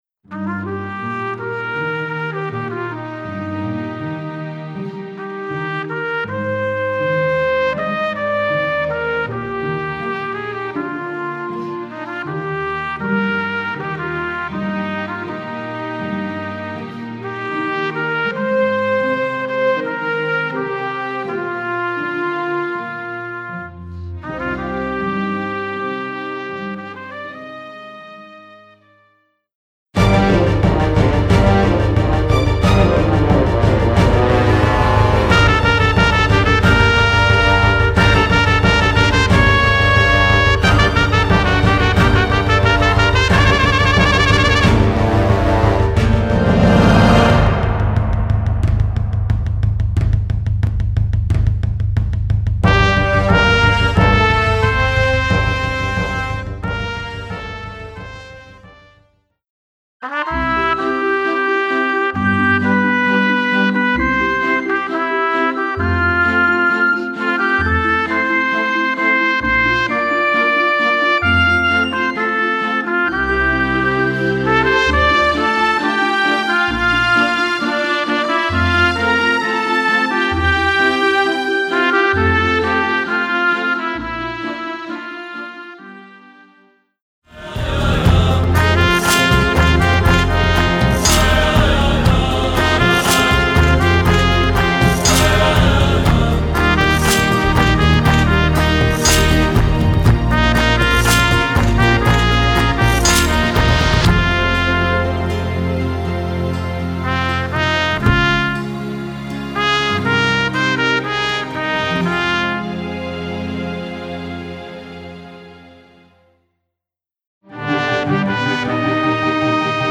Voicing: Trumpet w/ Audio